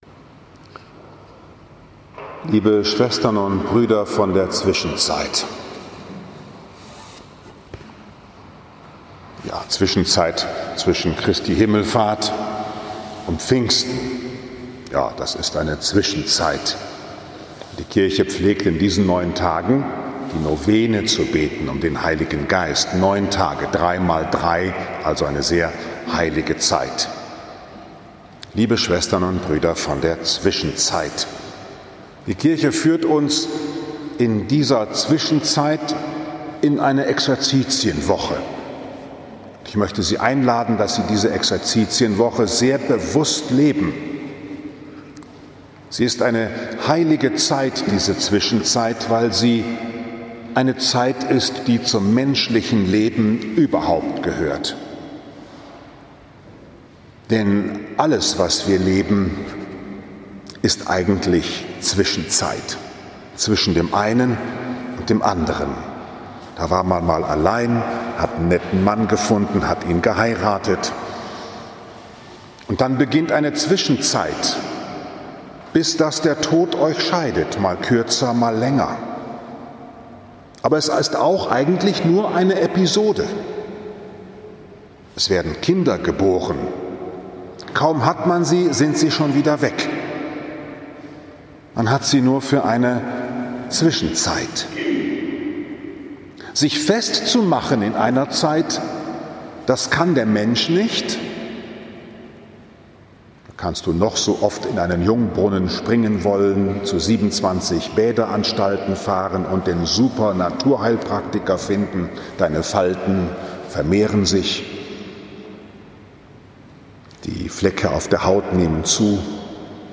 In der Zwischenzeit Mit gottgestifteter Freude die Welt gestalten, aber nicht an ihr festhalten 24. Mai 2020, 11 Uhr, Liebfrauenkirche Frankfurt am Main, 7. Sonntag der Osterzeit A